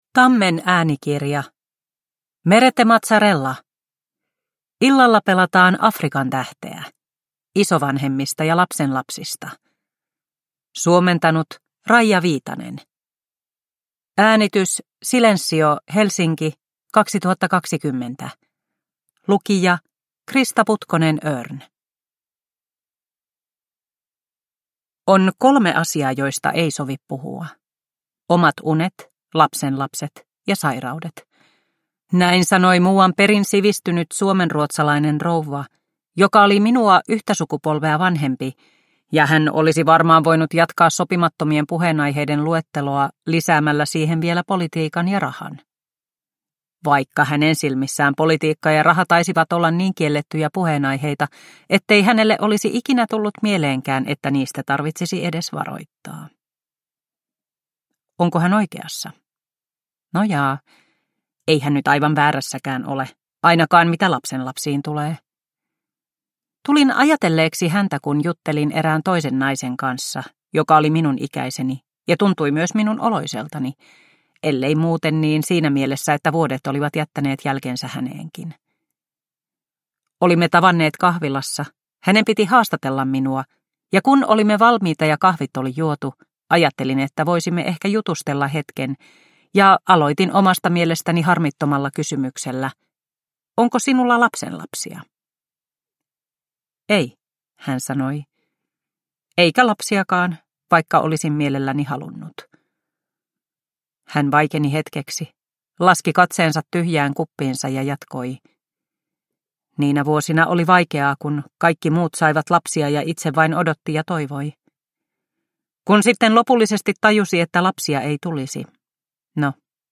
Illalla pelataan Afrikan tähteä – Ljudbok – Laddas ner